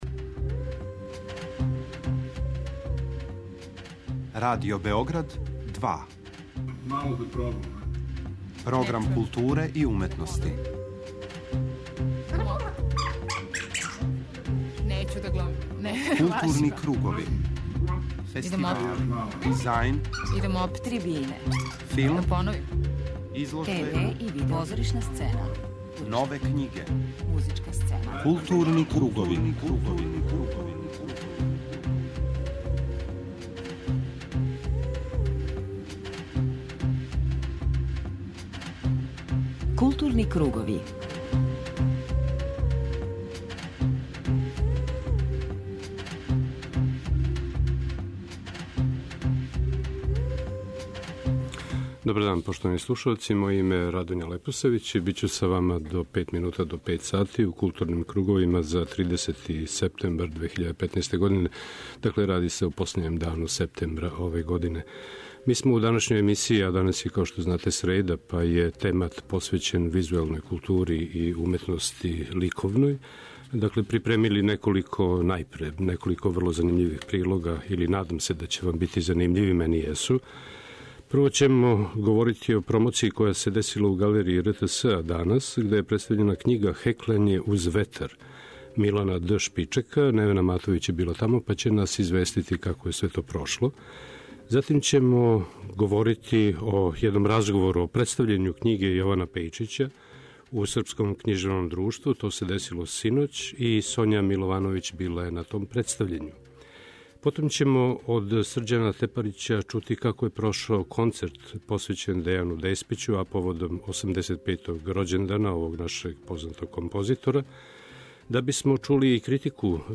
преузми : 52.08 MB Културни кругови Autor: Група аутора Централна културно-уметничка емисија Радио Београда 2.